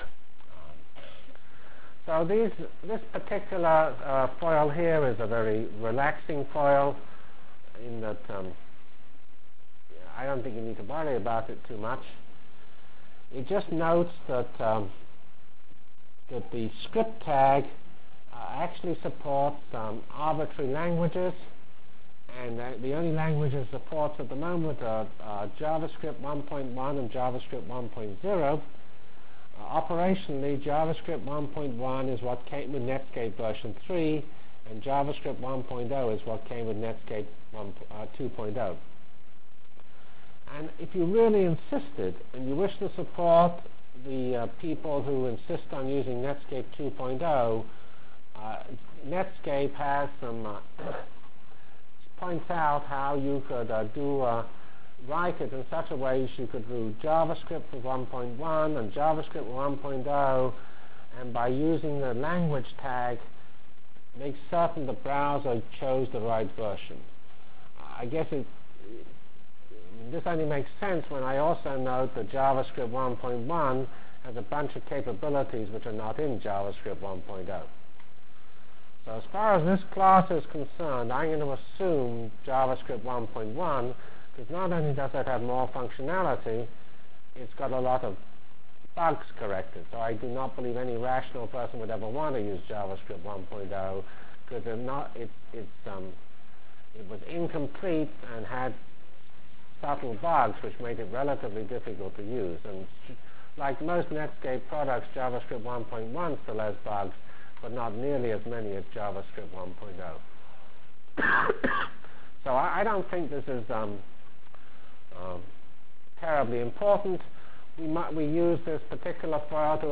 From Feb 12 Delivered Lecture for Course CPS616 -- Basic JavaScript Functionalities and Examples CPS616 spring 1997 -- Feb 12 1997.